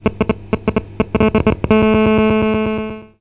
移动电话干扰
描述：这种效果通常在手机靠近电子设备如收音机或MP3播放器时出现。这是一个真实的干扰信号，已经被记录下来并稍作修改。